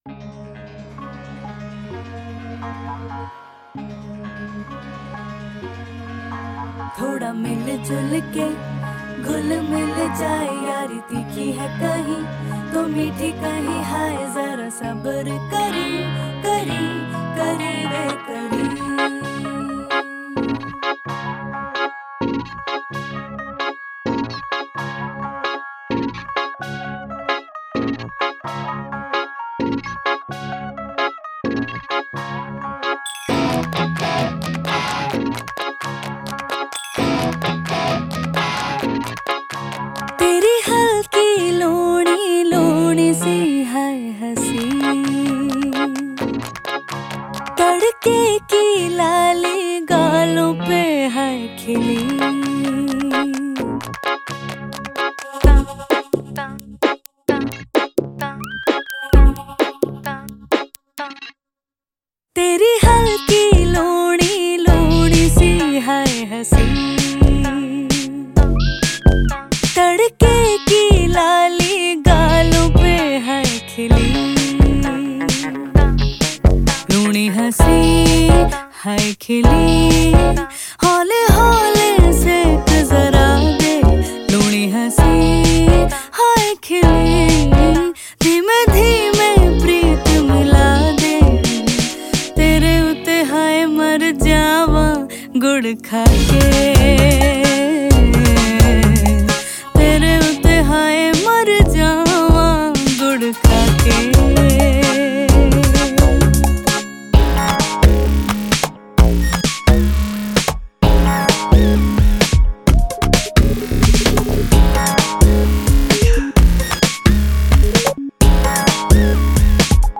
Bollywood MP3 Songs 2012